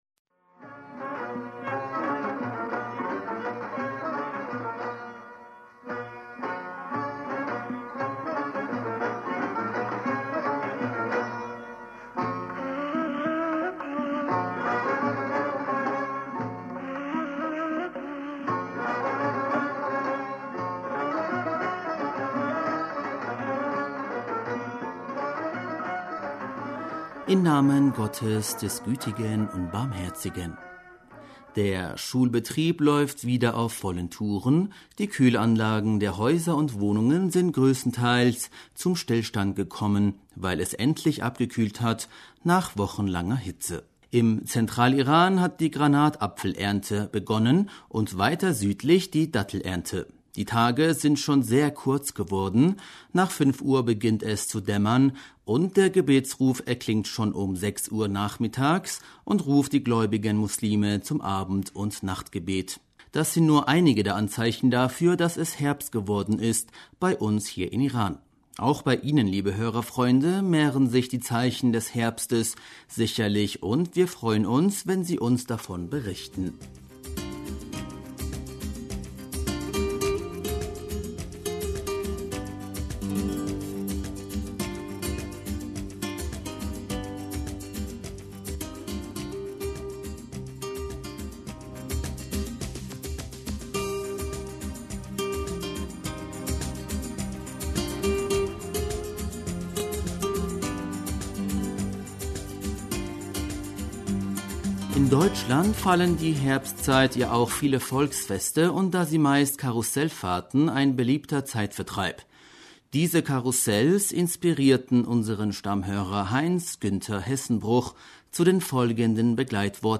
Hörerpostsendung am 06. Oktober 2019 Bismillaher rahmaner rahim - Der Schulbetrieb läuft wieder auf vollen Touren, die Kühlanlagen der Häuser und Wohnunge...